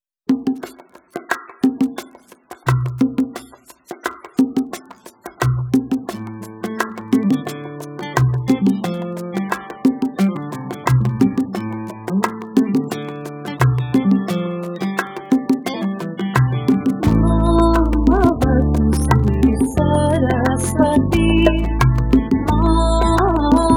tonal + transient + residual